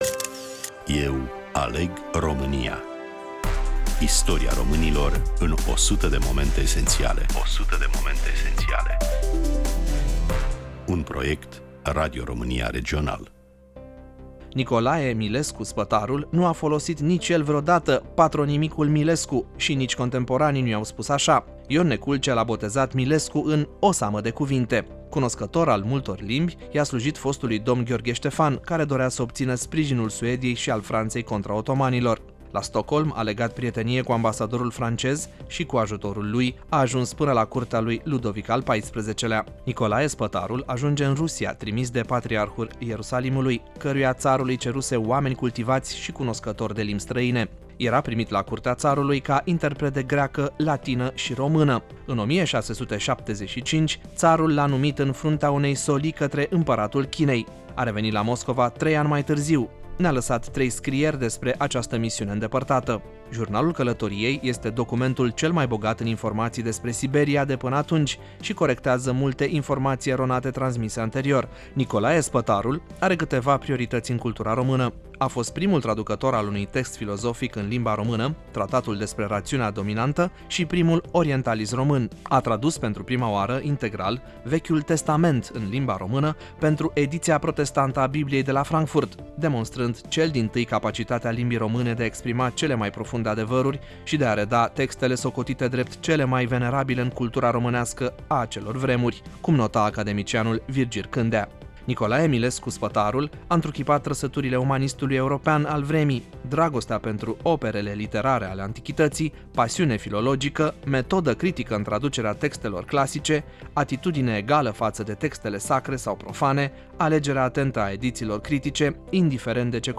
Prezentator / voice over